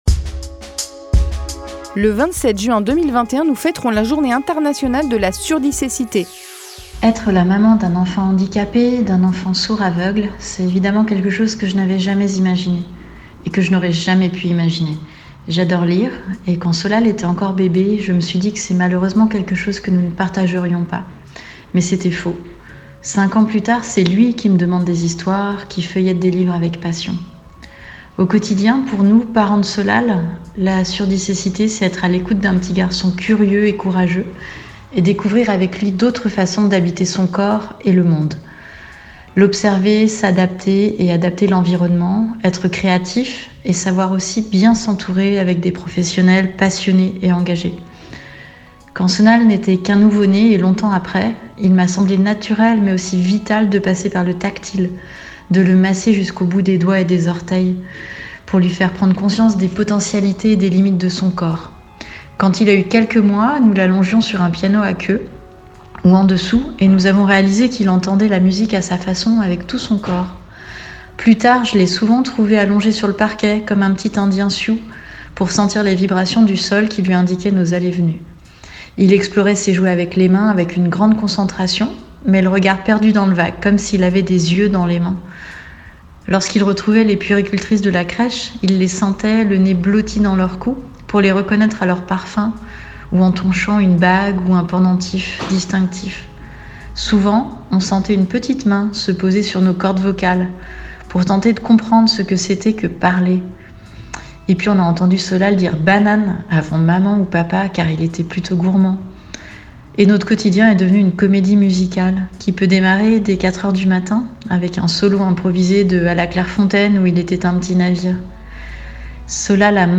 Les témoignages audio diffusés sur Styl’FM :